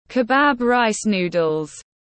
Bún chả tiếng anh gọi là kebab rice noodles, phiên âm tiếng anh đọc là /kɪˈbæb raɪs nuː.dəl/
Kebab rice noodles /kɪˈbæb raɪs nuː.dəl/